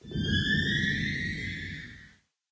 cave10.ogg